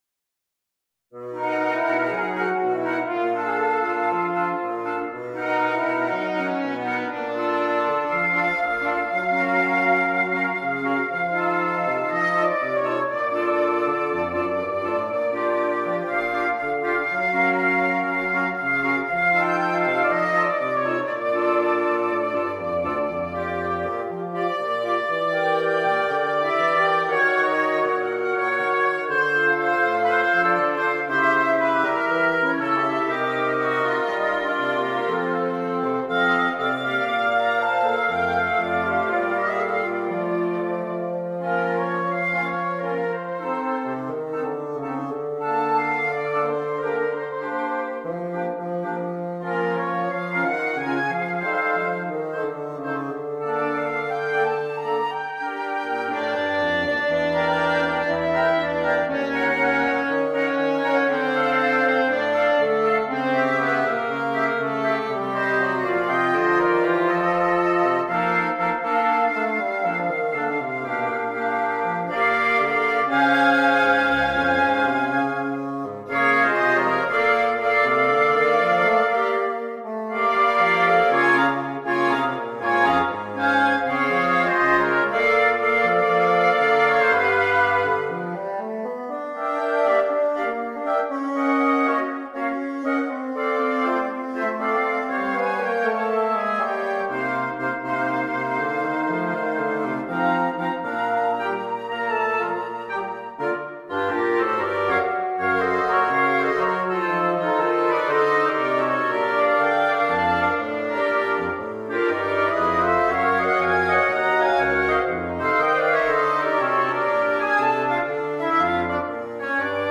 for Woodwind Quintet